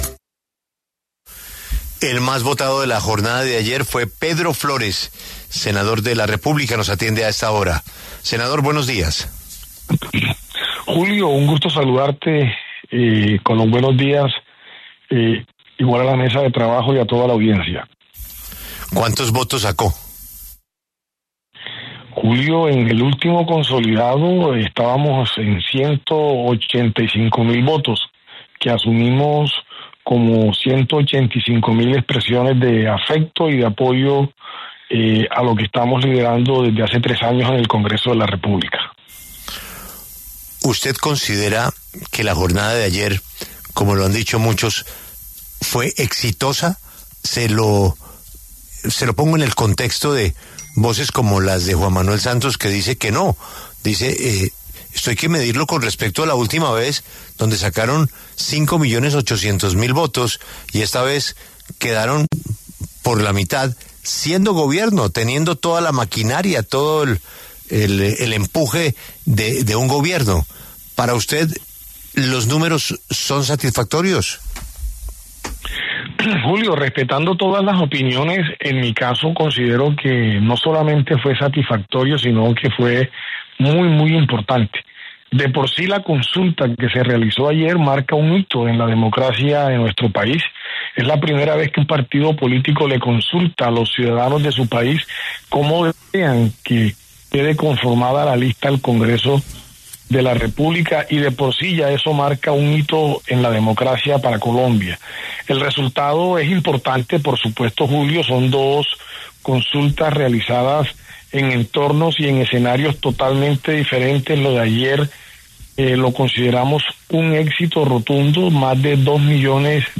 El senador Pedro Flórez, la votación más alta del Pacto Histórico al Senado en su consulta, pasó por los micrófonos de La W.